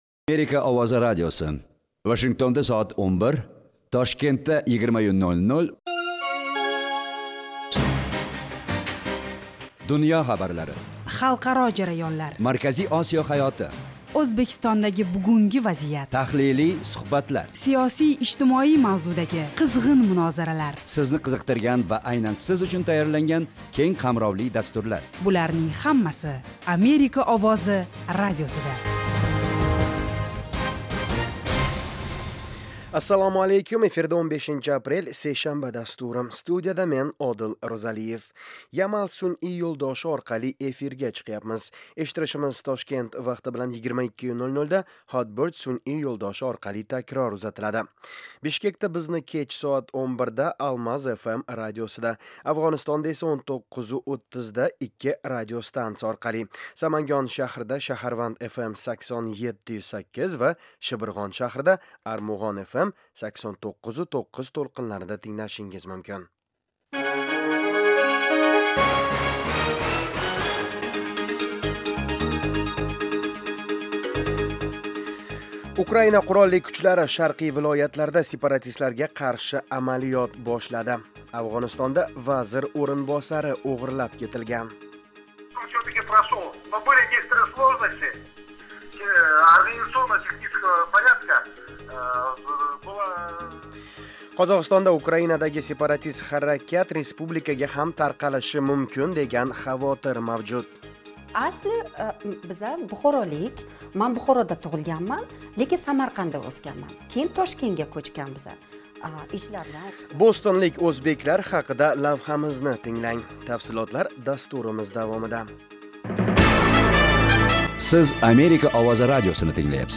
Toshkent vaqti bilan har kuni 20:00 da efirga uzatiladigan 30 daqiqali radio dastur kunning dolzarb mavzularini yoritadi. O'zbekiston va butun Markaziy Osiyodagi o'zgarishlarni tahlil qiladi. Amerika bilan aloqalar hamda bu davlat siyosati va hayot haqida hikoya qiladi.